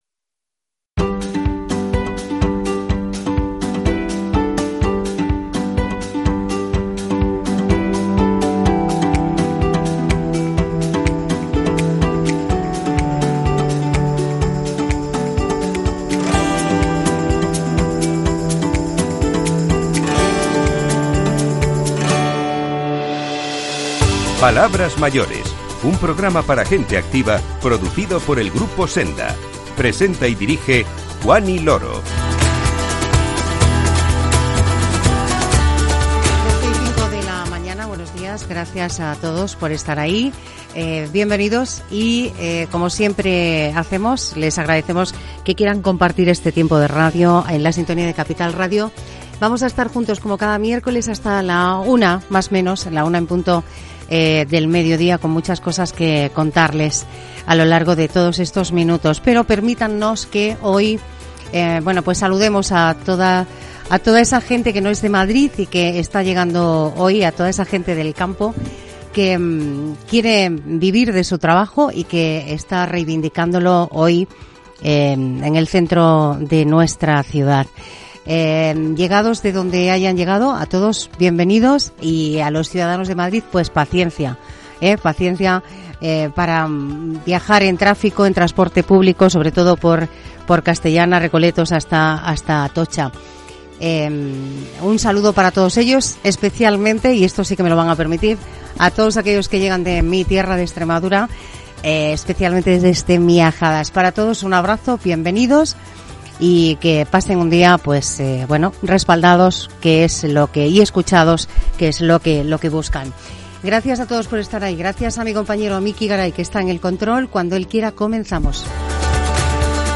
El director general del Mayor y de Atención a la Dependencia de la Comunidad de Madrid, Óscar Álvarez, nos visita para explicar las líneas maestras de su departamento.
Terminamos el programa recordando algunos de los acontecimientos que marcaron el año 1976, con la ayuda de algunos sonidos de aquel momento.